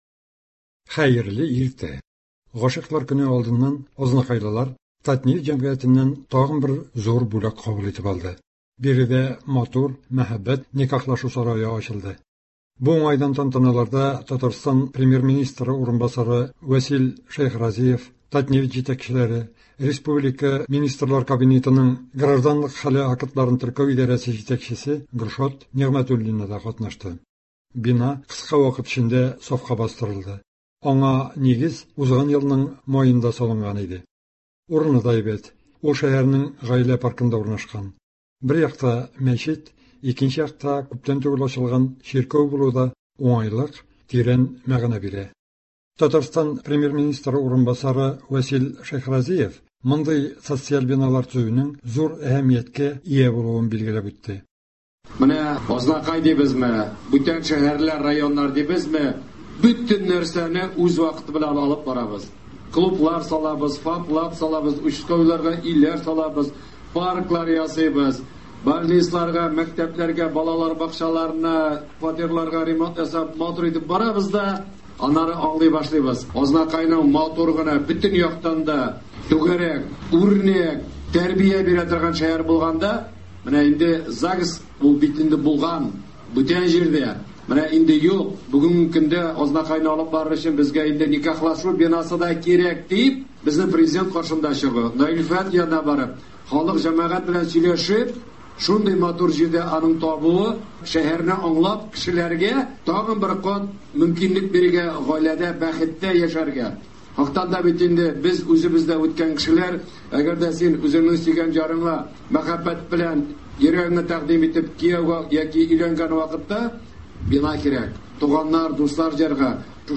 Азнакайда “Татнефть” җәмгыяте ярдәме белән Никахлашу сарае төзелде. Тапшыруда аны ачу тантанасыннан репортаж бирелә.